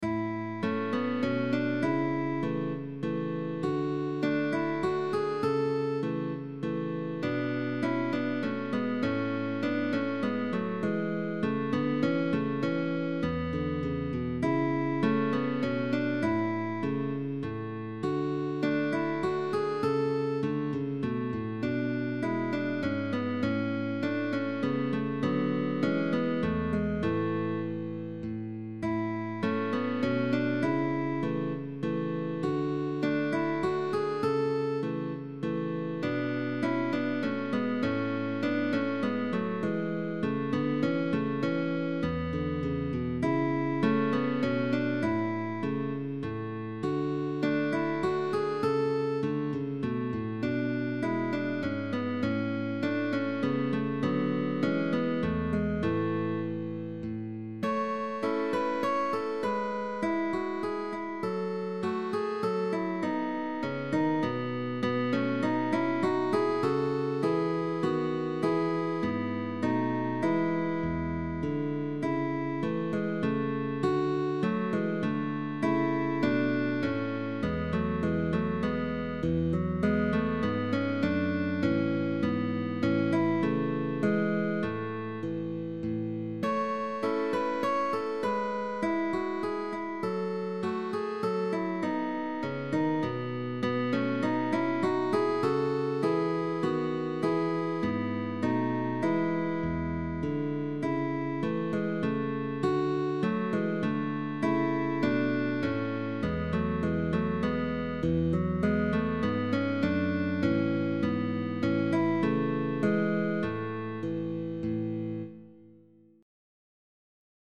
Baroque